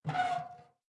mailbox_open_1.ogg